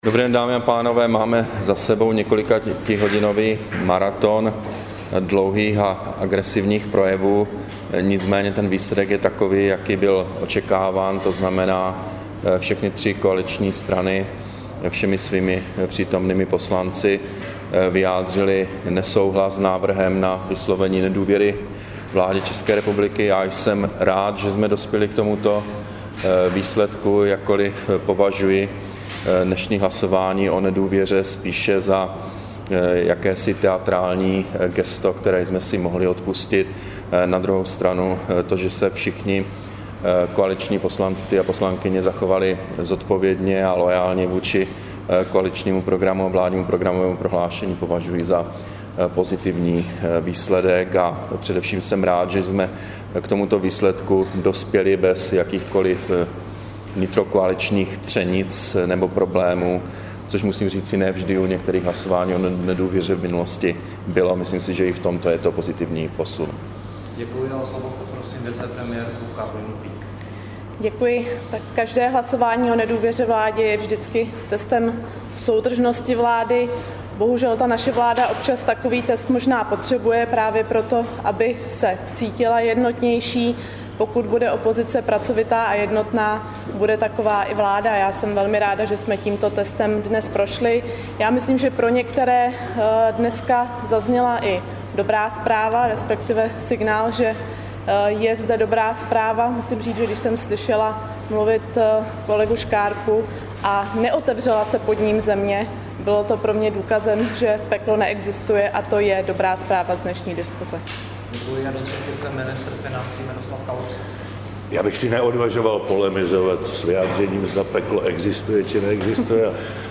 Brífink vlády po hlasování o nedůvěře, 20. března 2012